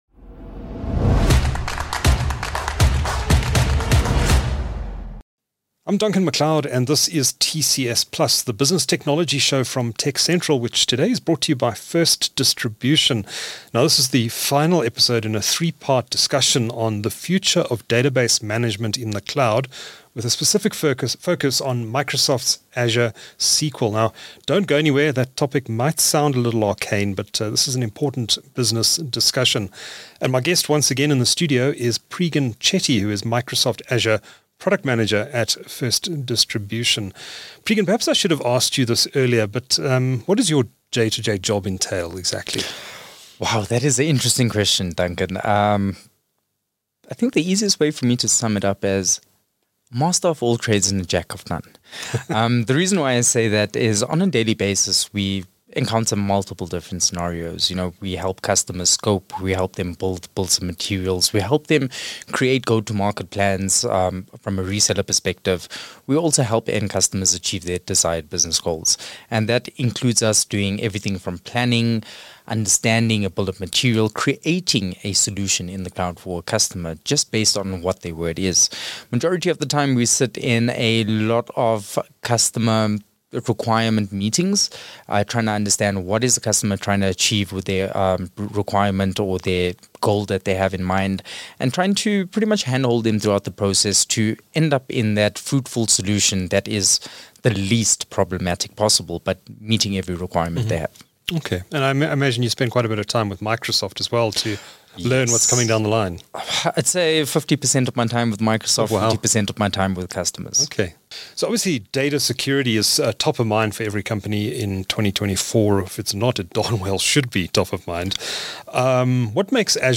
TechCentral's TCS+ is a business technology show that brings you interviews with leaders in South Africa's technology industry - and further afield. It showcases the latest products and services available to businesses large and small.